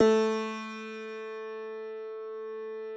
53l-pno09-A1.wav